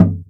Bongo24.wav